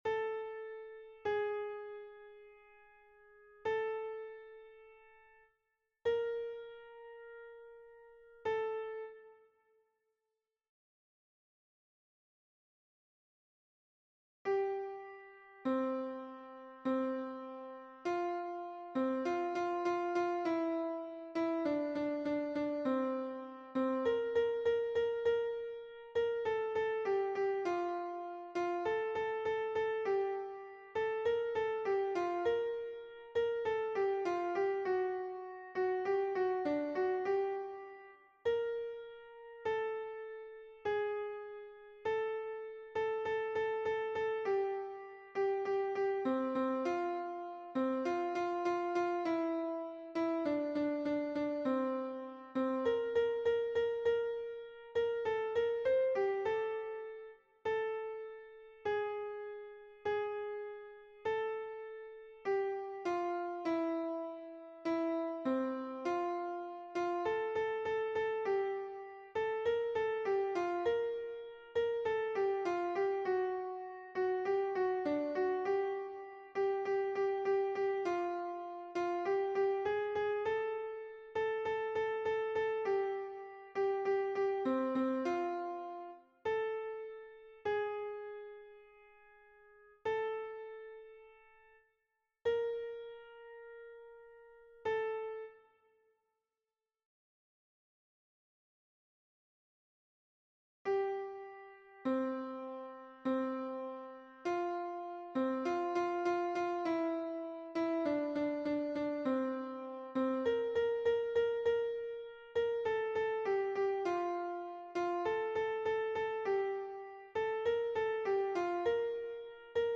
MP3 version piano
Hommes